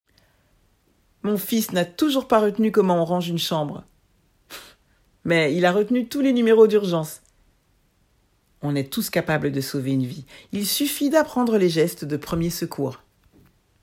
25 - 60 ans - Soprano